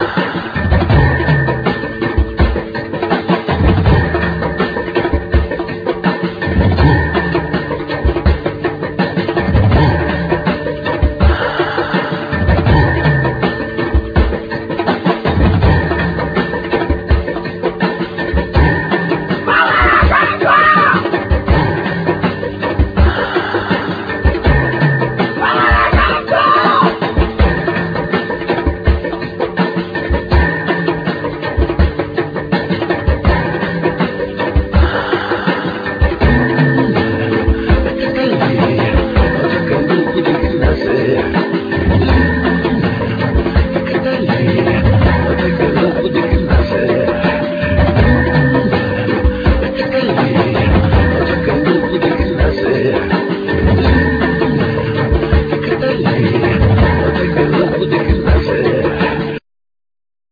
Vocal,Throat vocal,Djembe,Rattles,Indian flute,Sounds
Drums,Percussions,Groove box,Synth,Loop
Bass Hammond,Synth,Sample
Guitar loop